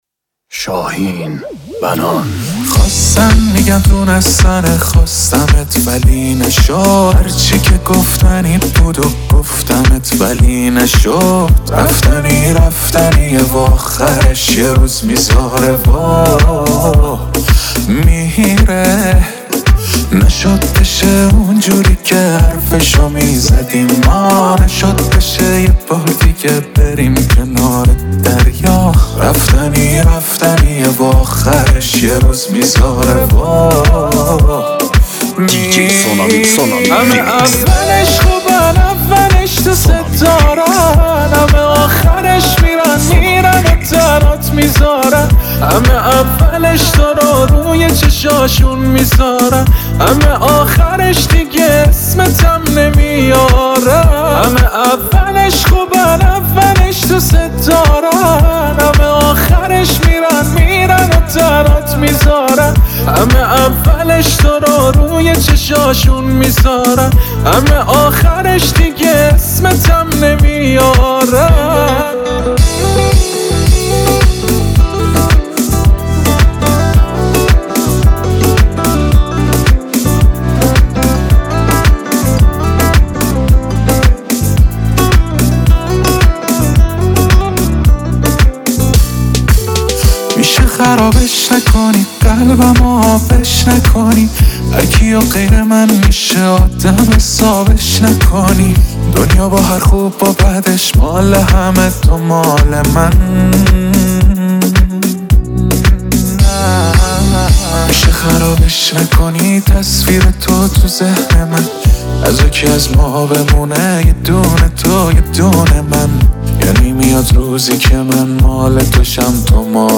دانلود ورژن ریمیکس این آهنگ